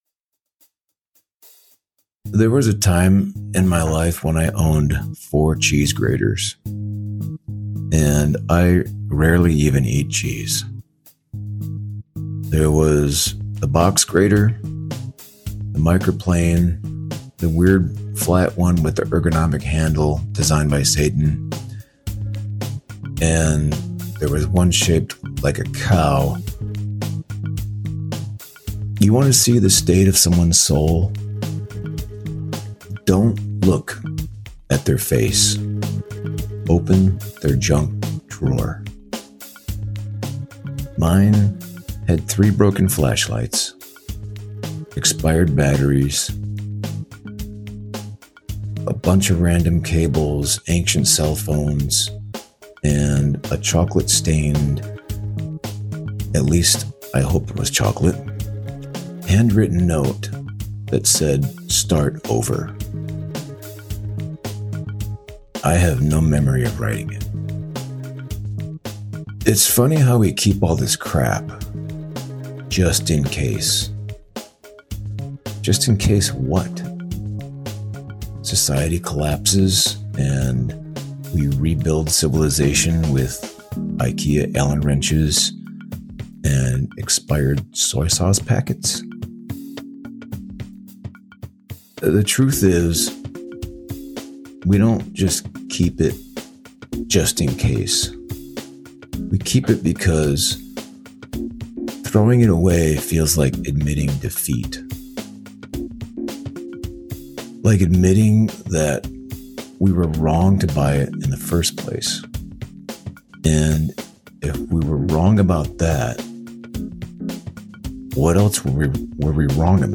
A spoken word performance